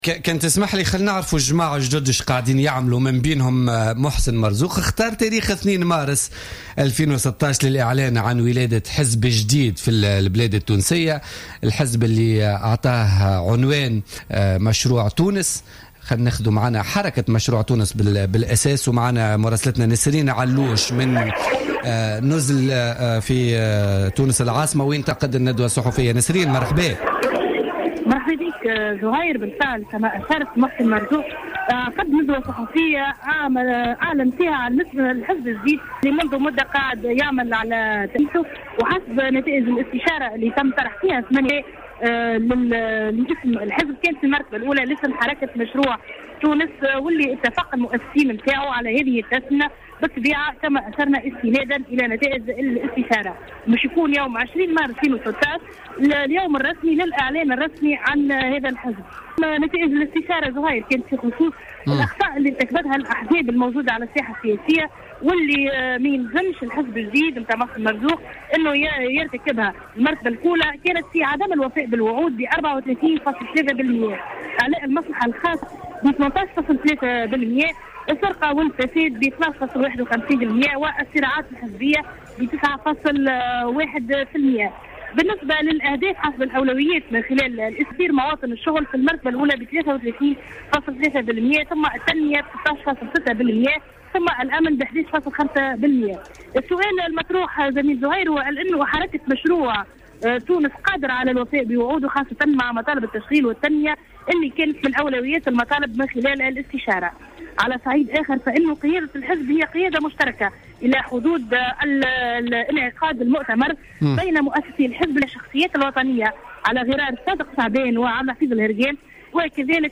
تم اليوم الأربعاء 02 مارس 2016 خلال ندوة صحفية الإعلان عن اسم الحزب الجديد لمحسن مرزوق و هو "حركة مشروع تونس" وذلك بعد استشارة عقدها الحزب بمختلف ولايات الجمهورية.